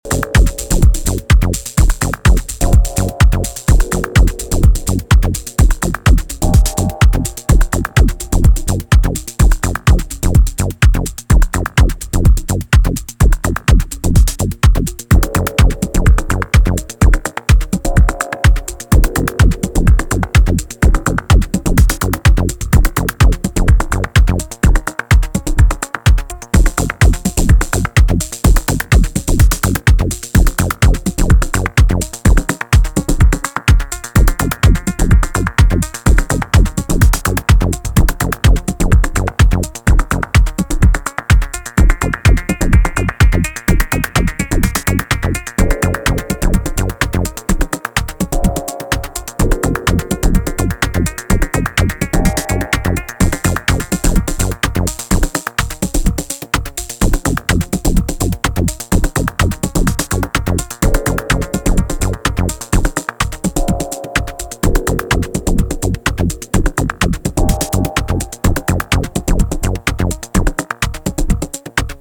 ダスティなジャッキングルーヴに繊細なシンセのレイヤーが施されて神秘的なムードの